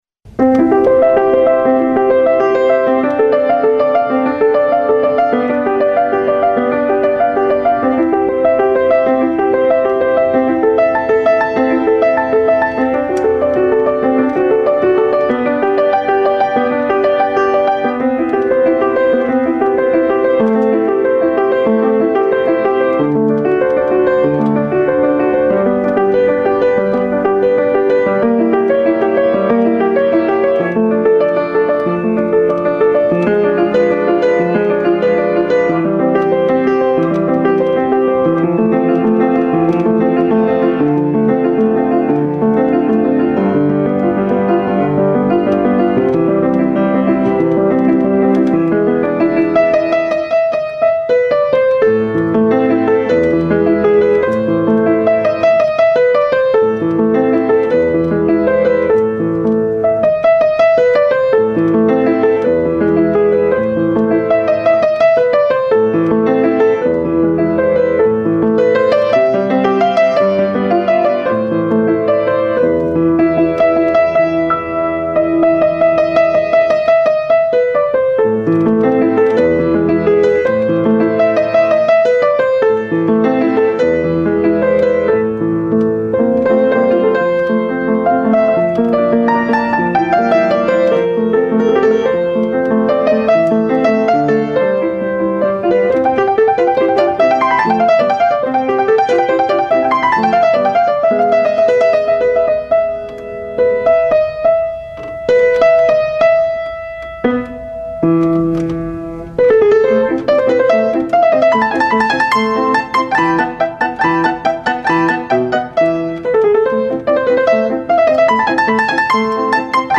Potpourri
No repetition, no connection between sections. Contains favourite tunes of popular pieces of music.
Popular classic piano potpourri.mp3